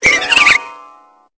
Cri de Roserade dans Pokémon Épée et Bouclier.